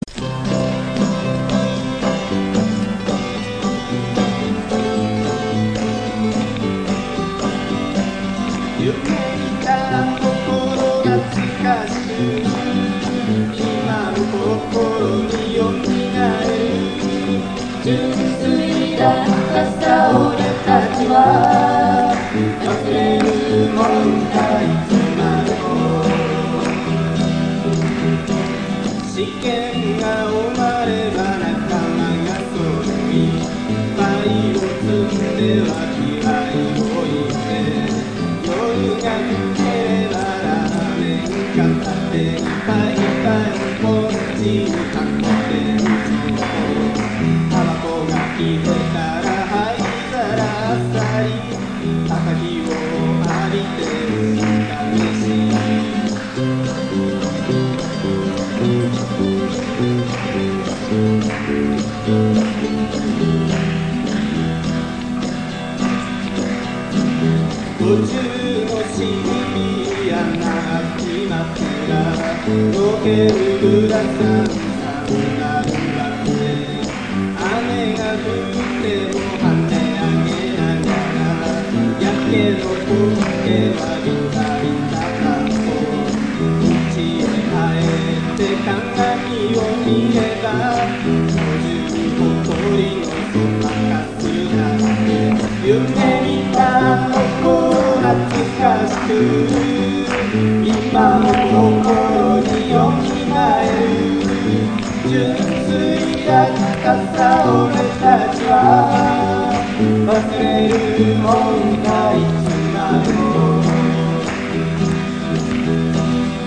ギター -オリジナル曲-
ライブ版。
バンドは男２人と女３人。
楽器はギター３台とタンバリン他で、アコースティックが中心でした。
当時のテープ録音をサウンドレコーダーで編集したので、音はモノラルです。